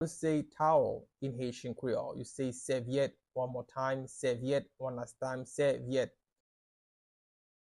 Pronunciation:
How-to-say-Towel-in-Haitian-Creole-Sevyet-pronunciation-by-a-Haitian-translator.mp3